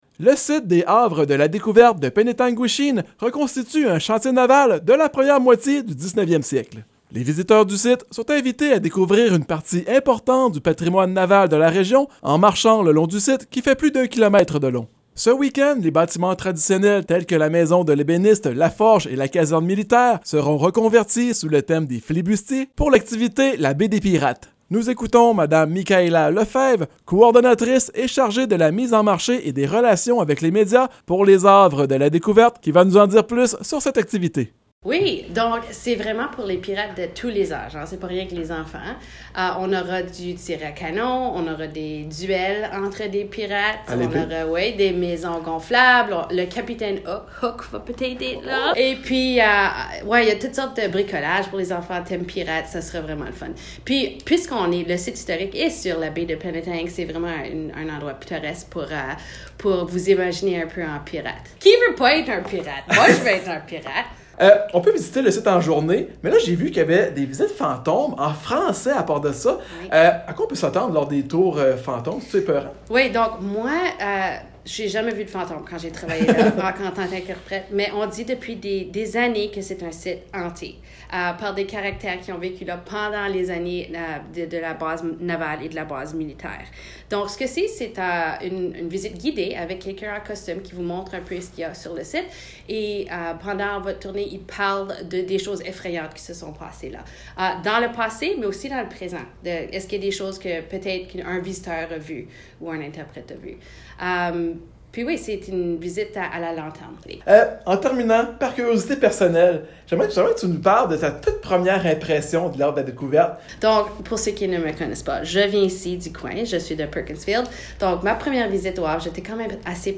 2Entrevue-Havres.mp3